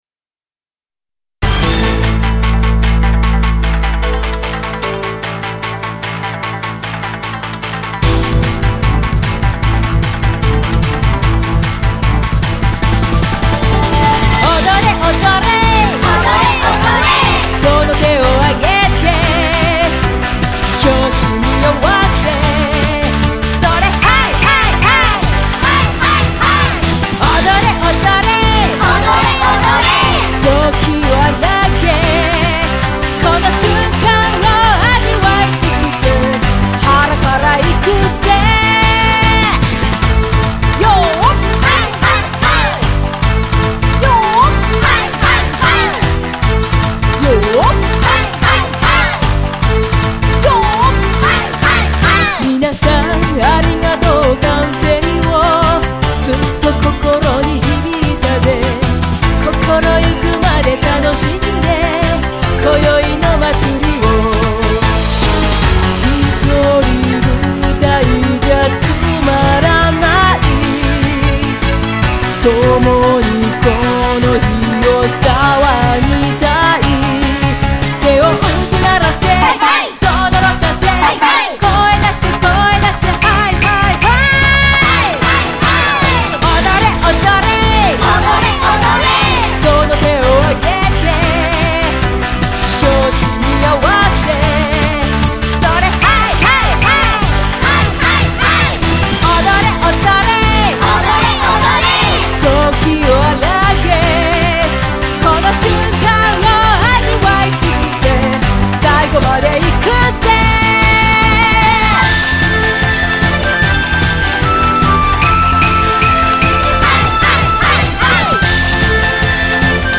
「掛け声参加」を募らせていただきました。
ぜひ、一緒に「ハイ！ハイ！ハイ！」と叫んでくださいまし！！(≧▽≦)